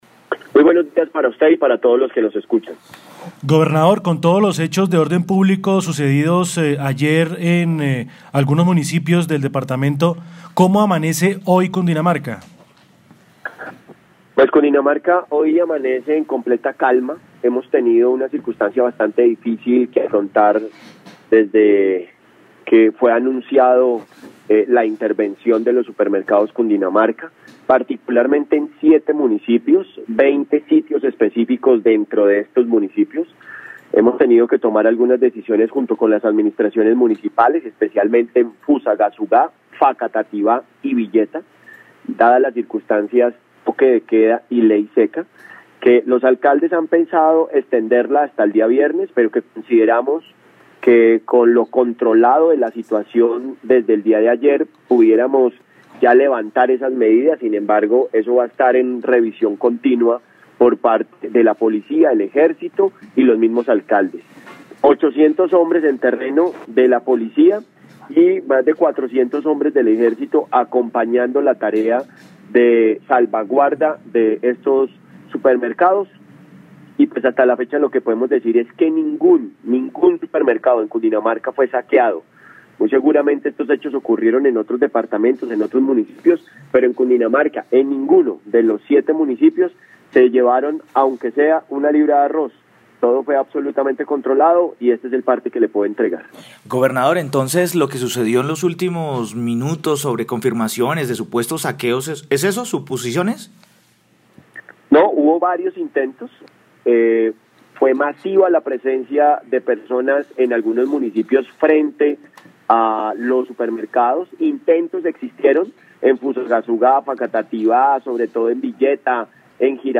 En diálogo con UNIMINUTO Radio estuvo Jorge Emilio Rey, gobernador del departamento de Cundinamarca entregando el reporte de orden público luego de los desmanes ocurridos el pasado 20 de febrero contra algunos supermercados de varios municipios.